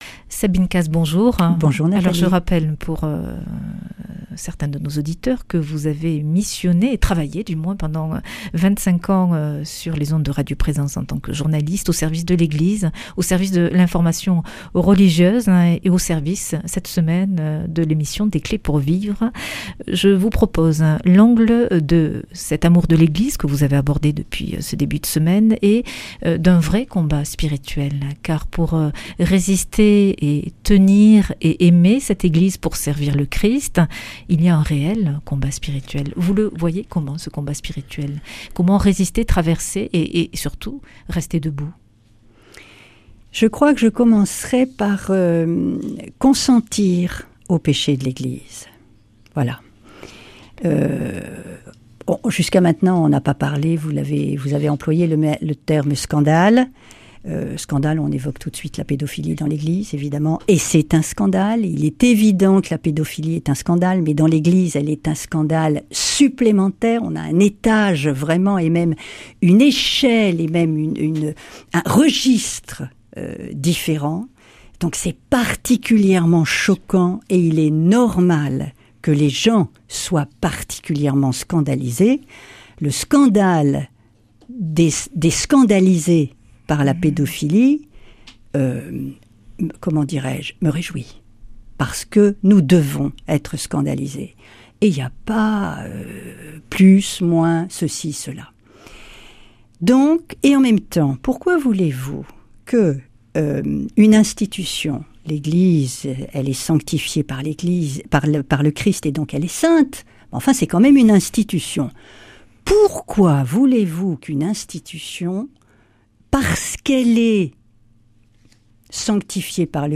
Speech
Une émission présentée par